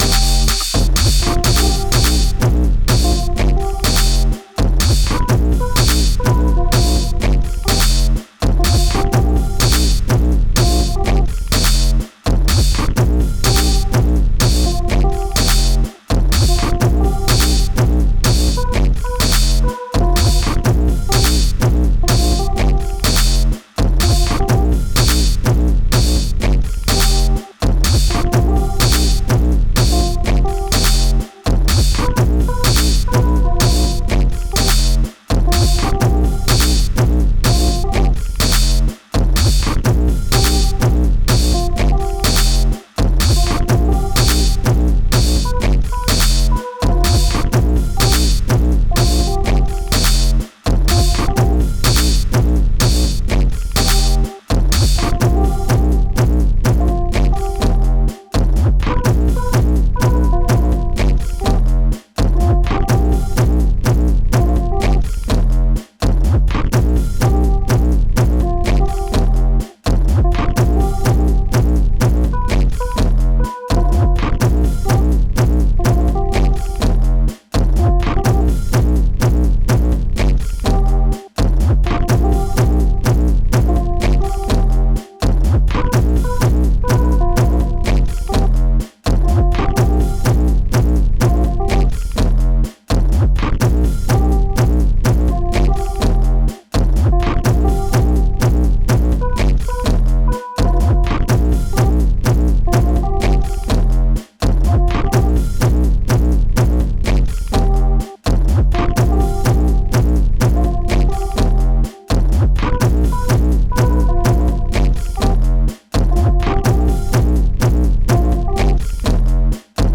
Techno sperimentale e elettronica sperimentale